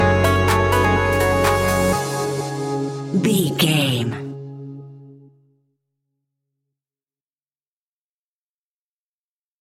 Aeolian/Minor
C#
groovy
hypnotic
synthesiser
drum machine
funky house
deep house
upbeat
funky guitar
synth bass